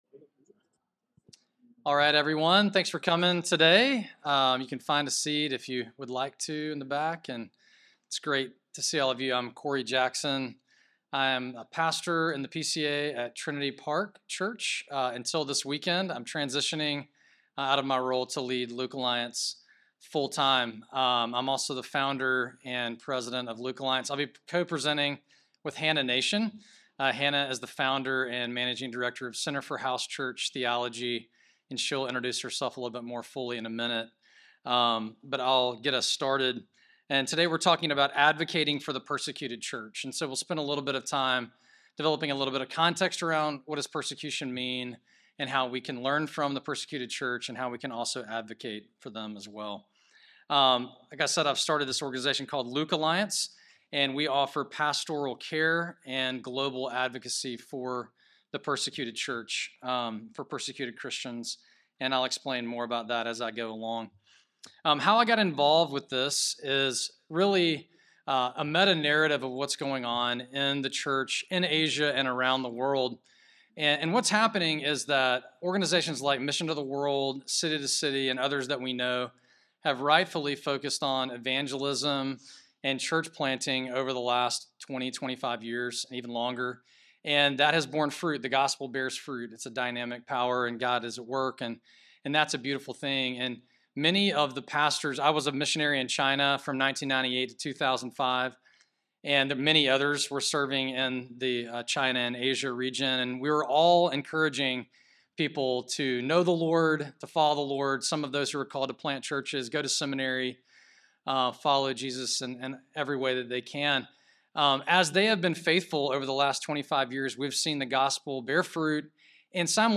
Through the lens of the persecuted church’s experience in China, this seminar will explore a theology of advocacy and will include practical ways that you and your churches can join an alliance of global advocates.